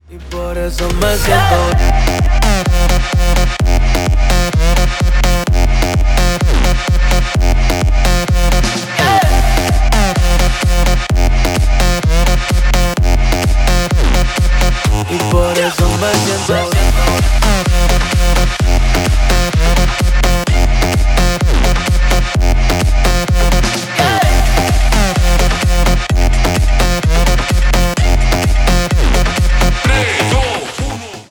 бесплатный рингтон в виде самого яркого фрагмента из песни
Танцевальные
клубные # громкие